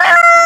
NRG Vocals-2.wav